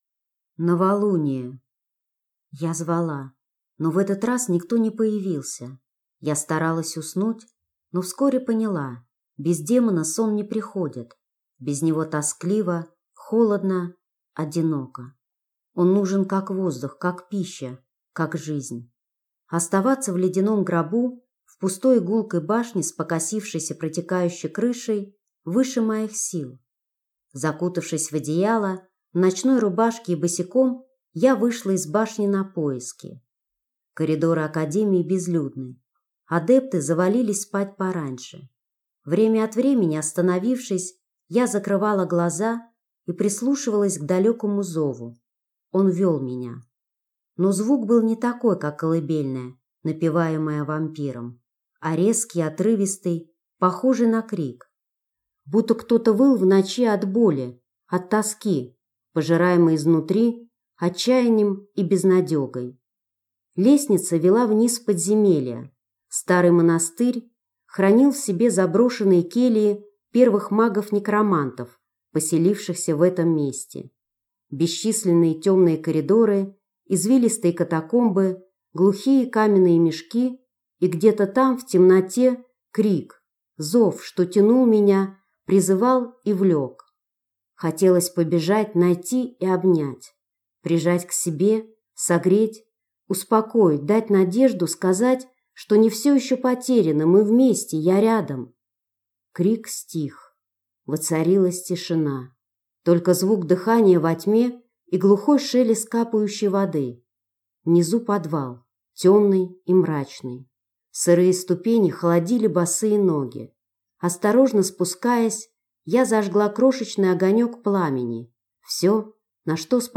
Аудиокнига Живая Академия | Библиотека аудиокниг